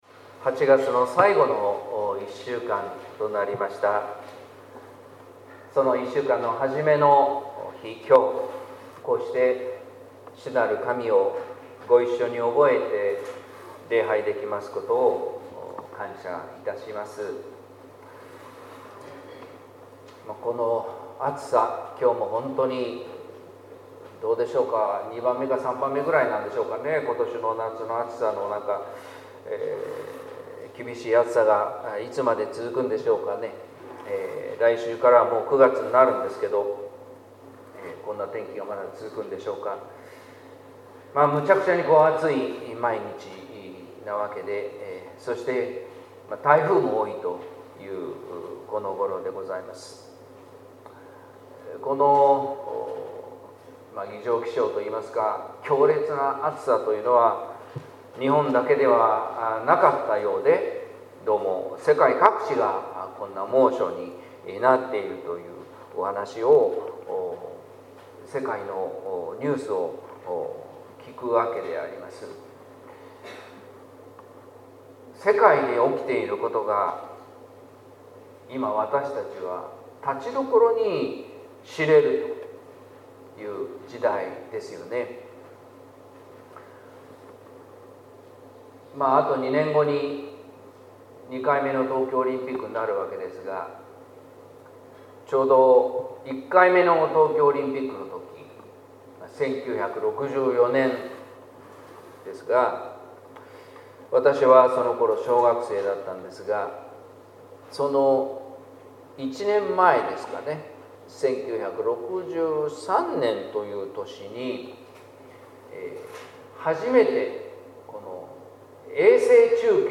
説教「伝えたい三つの言葉」（音声版） | 日本福音ルーテル市ヶ谷教会